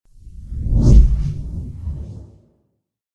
Звуки облака, мыслей
С эффектом вжух